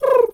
Animal_Impersonations
pigeon_2_call_10.wav